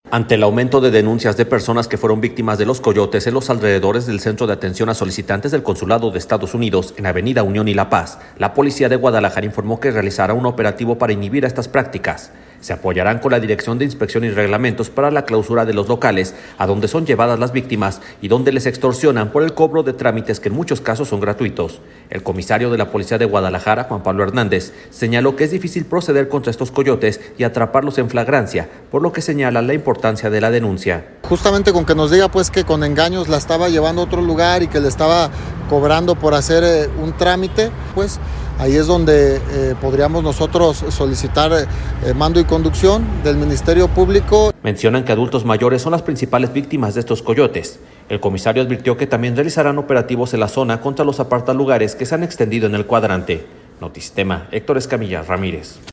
Se apoyarán con la Dirección de Inspección y Reglamentos para la clausura de los locales a donde son llevadas las víctimas y donde les extorsionan por el cobro de trámites que en muchos casos son gratuitos. El comisario de la Policía de Guadalajara, Juan Pablo Hernández, señaló que es difícil proceder contra estos coyotes y atraparlos en flagrancia, por lo que señalan la importancia de la denuncia.